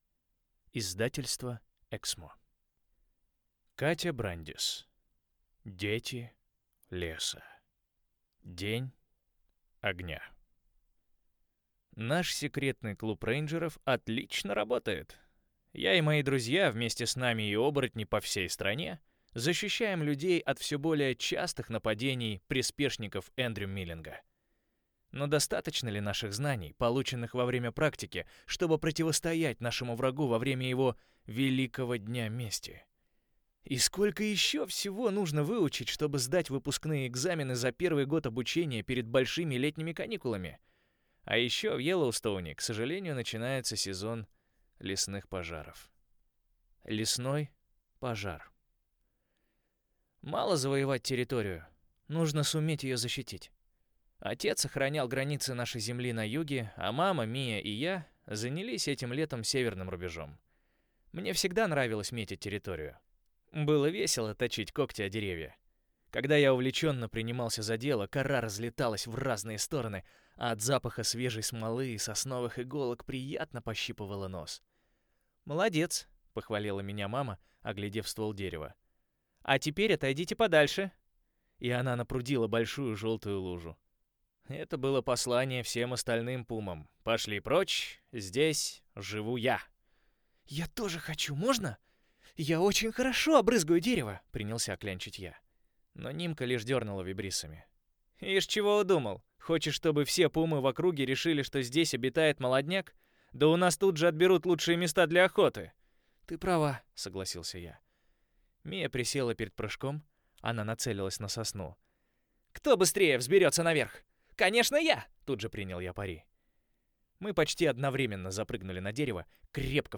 Аудиокнига День огня | Библиотека аудиокниг